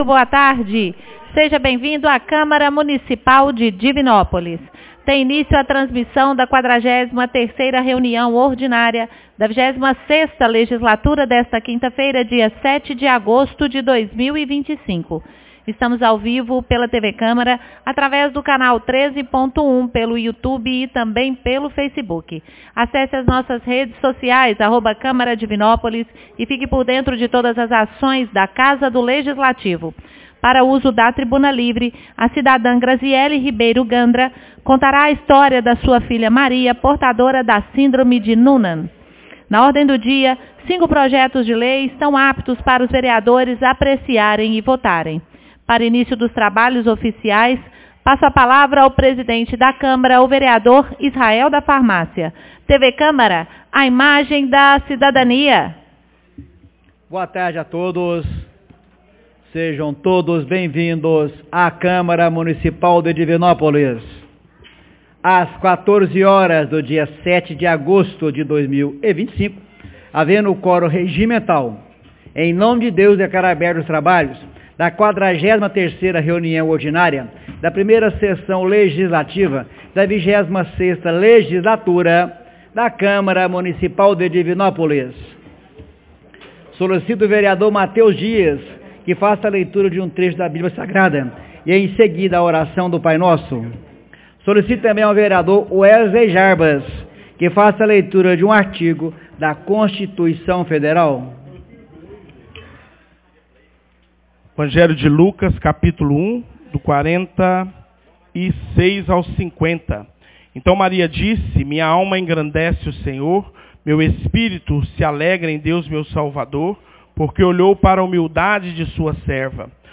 43ª Reunião Ordinaria 07 de agosto de 2025